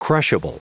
Prononciation du mot crushable en anglais (fichier audio)
Prononciation du mot : crushable
crushable.wav